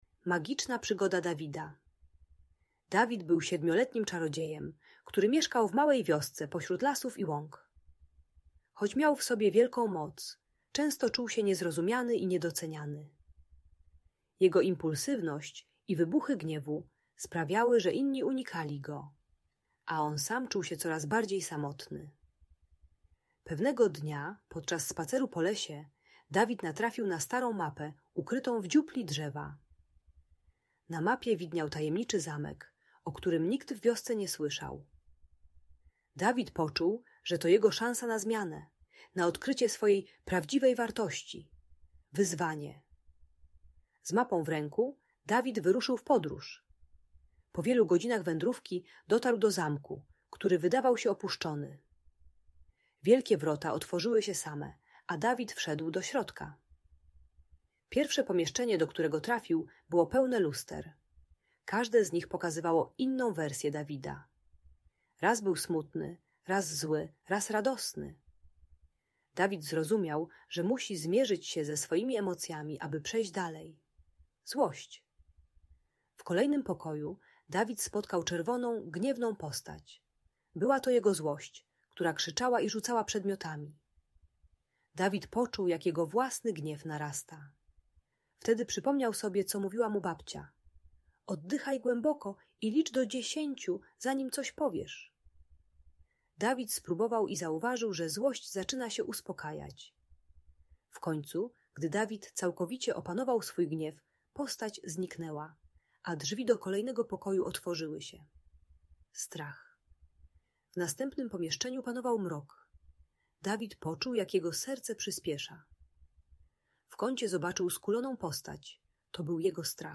Magiczna Przygoda Dawida - Opowieść o Emocjach - Audiobajka dla dzieci